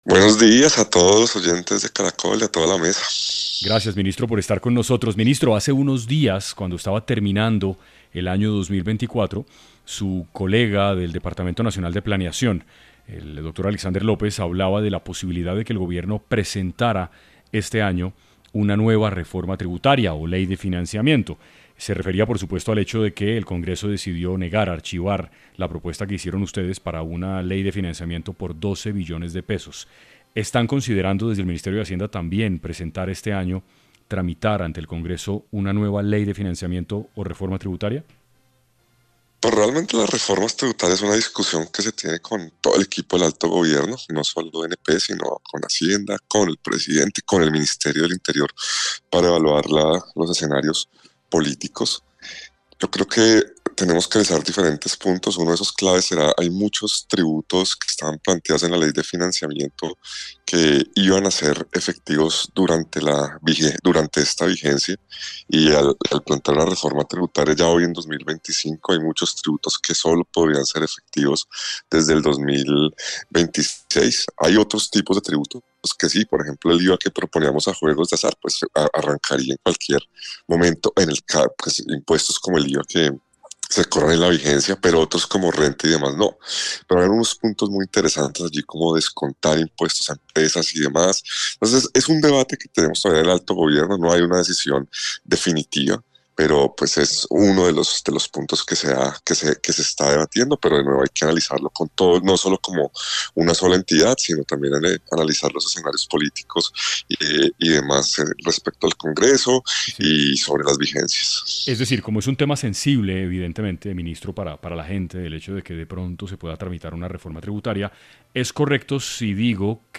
En Caracol Radio estuvo Diego Guevara, ministro de Hacienda, compartiendo detalles sobre lo que será este 2025 en términos financieros para Colombia